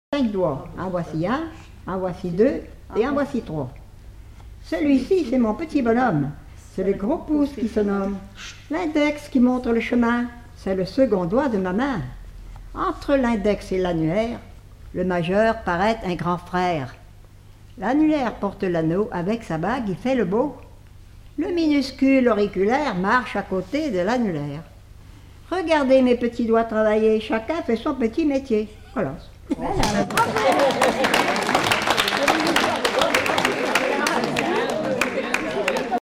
formulette enfantine : jeu des doigts
Collectif-veillée (1ère prise de son)
Pièce musicale inédite